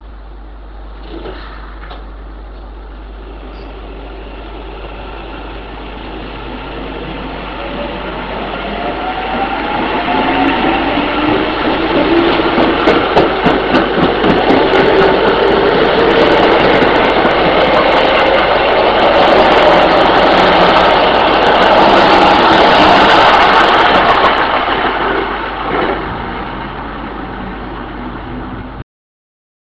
０５系後期車加速音 北習志野→飯山満 78.8Kb RealAudio形式
最初の高い音が聞こえるでしょうか？